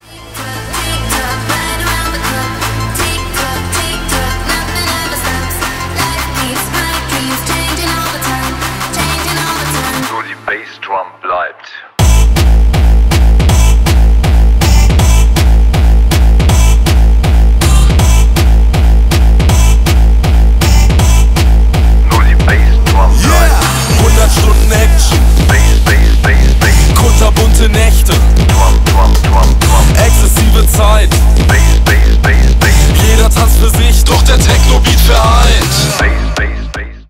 bass boosted
басы